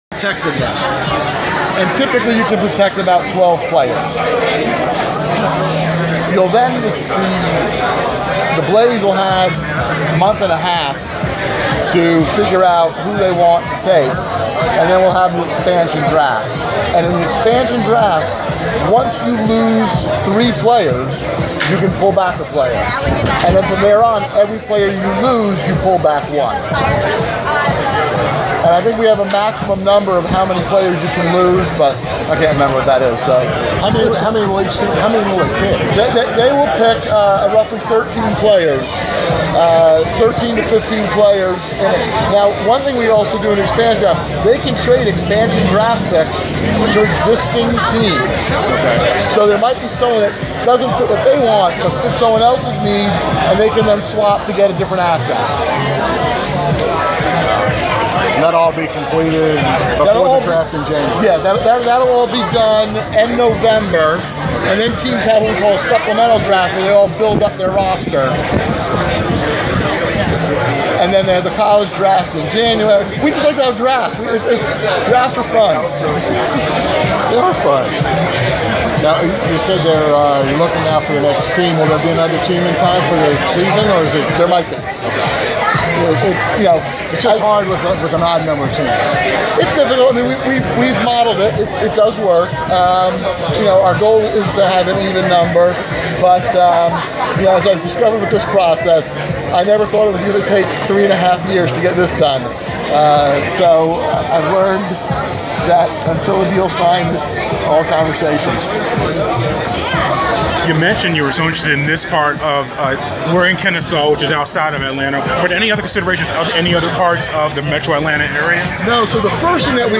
Inside the Inquirer: Interview
The Sports Inquirer attended the launch of the newest Major League Lacrosse franchise, the Atlanta Blaze, on August 7.